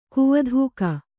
♪ kuvadhūka